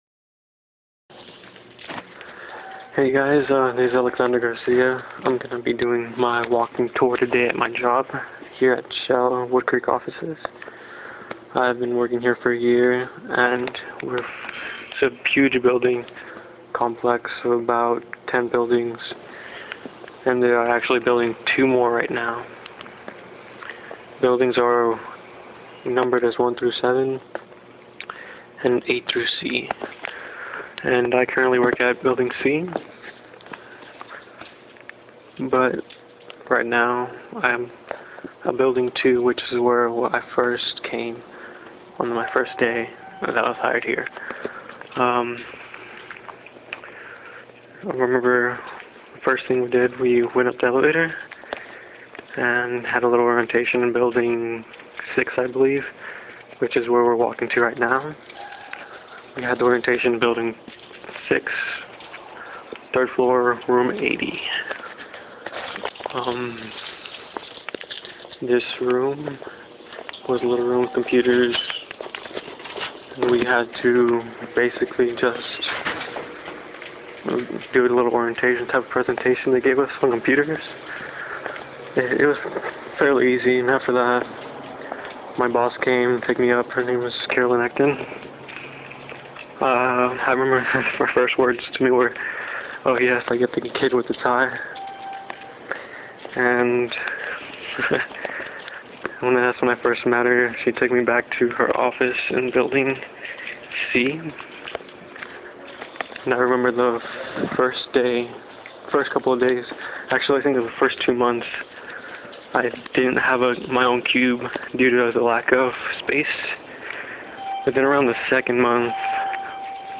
In this tour , I take you throughout the Shell Woodcreek Office Buildings . I explain a variety of things about shell, from thier safety regulations to things such as what the employees' there drive.